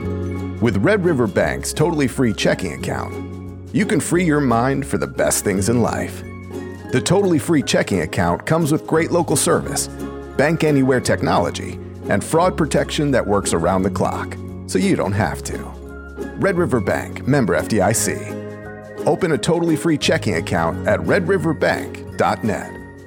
Male
-Neumann TLM 103 Condensor Microphone
Podcasting
Red River Bank Midwestern
Words that describe my voice are real, conversational, youthful.
0814Red_River_demo.mp3